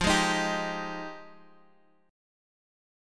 Fm9 Chord
Listen to Fm9 strummed